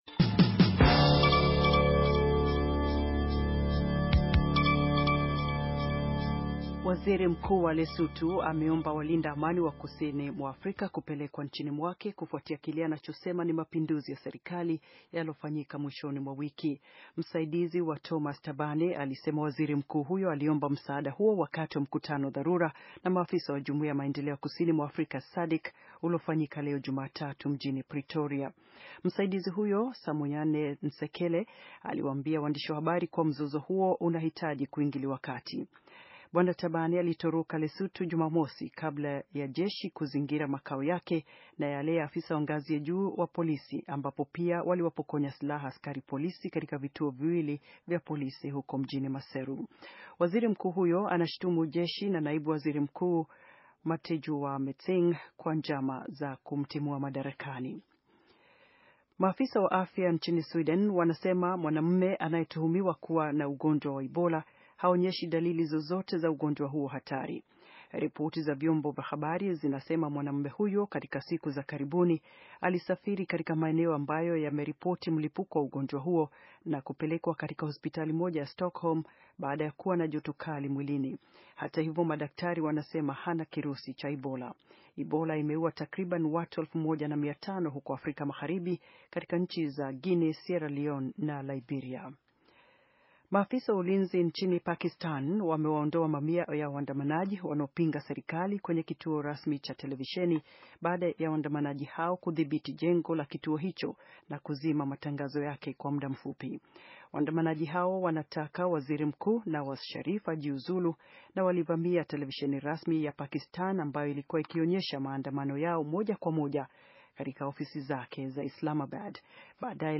Taarifa ya habari - 5:19